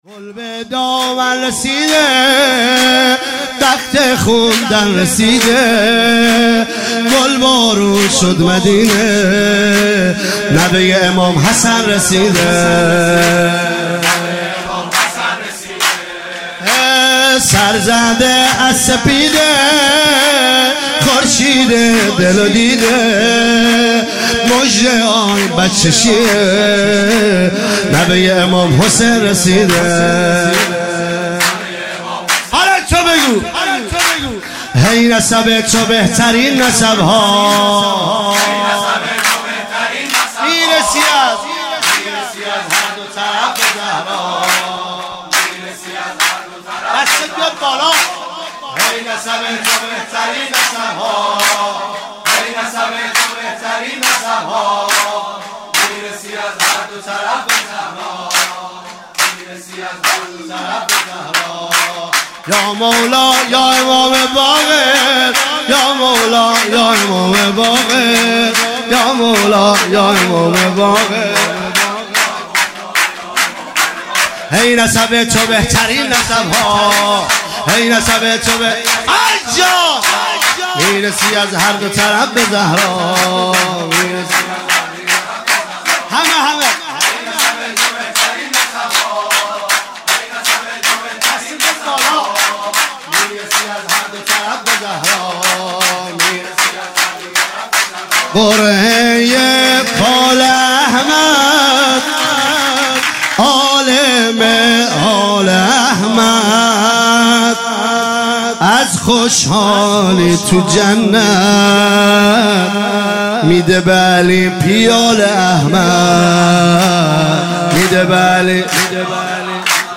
ولادت امام باقر علیه السلام